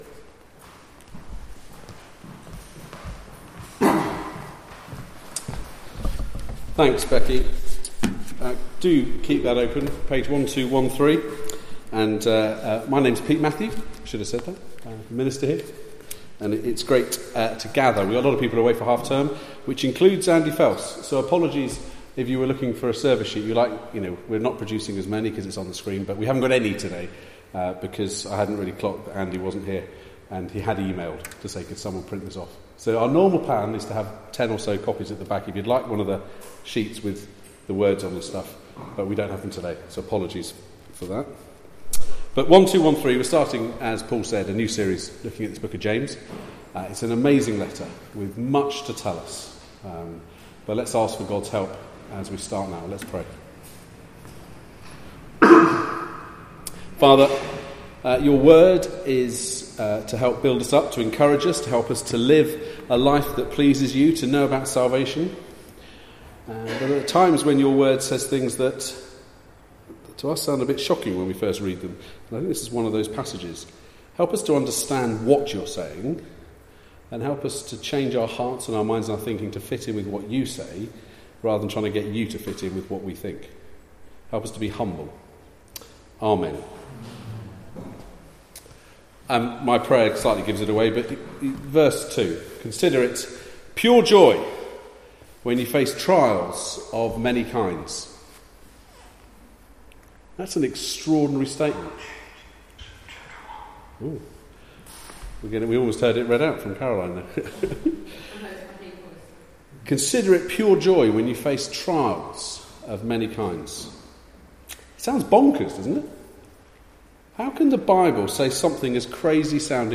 Passage: James 1: 1-8 Service Type: Weekly Service at 4pm